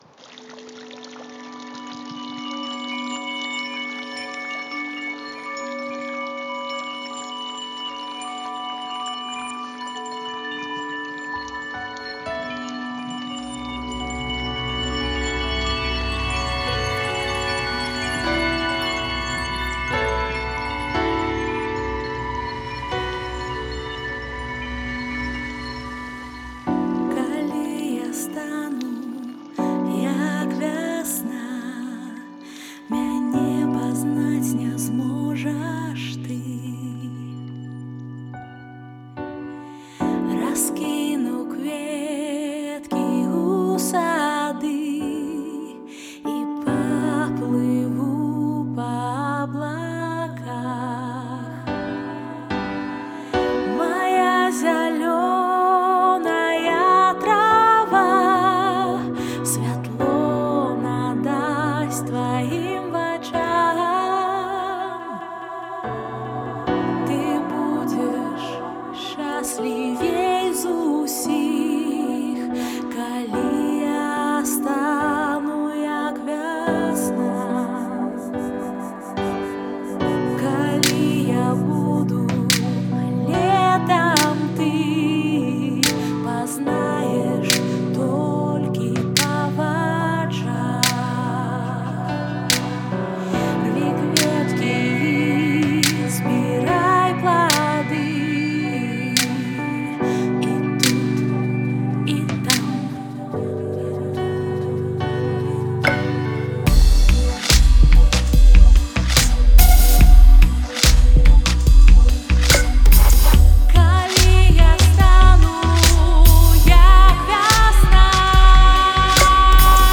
які можна назваць клясыкамі беларускай электроннай сцэны